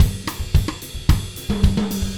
Звуки латино
Latin Drum Fill Groove 1